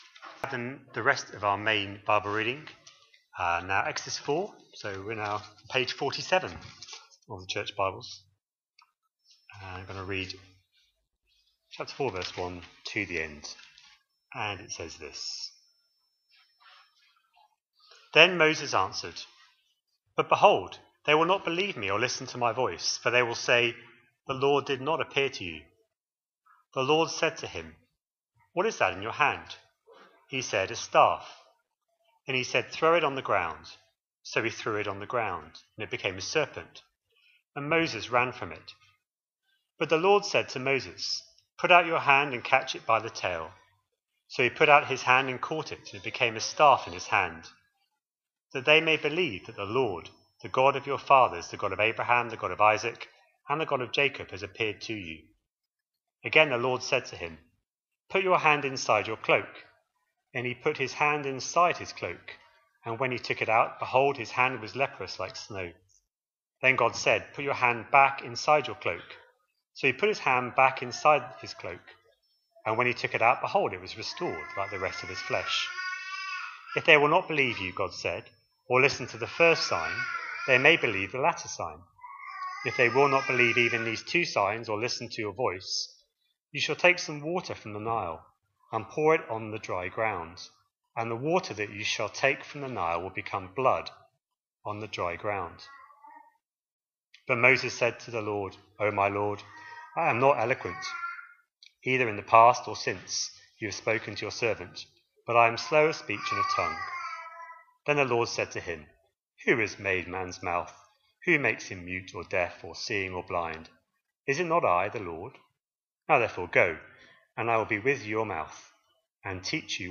A sermon preached on 20th July, 2025, as part of our Exodus series.